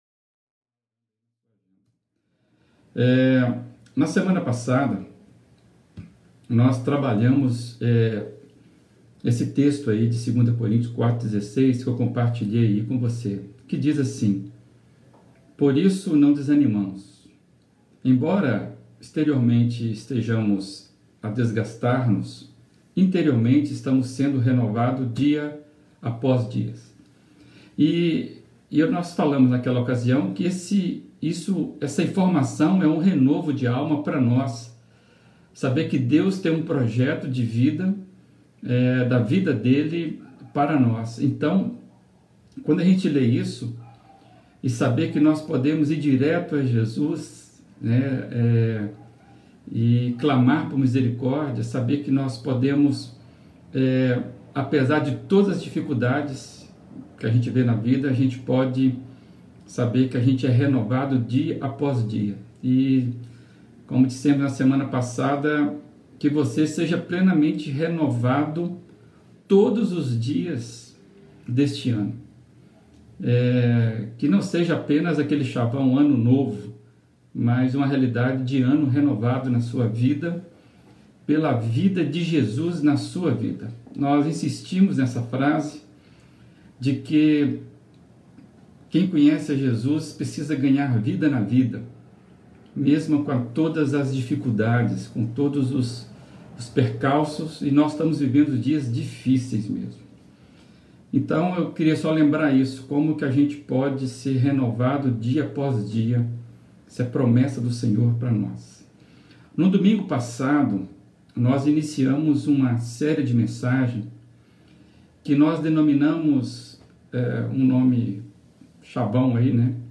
Reflexões das Quartas-feiras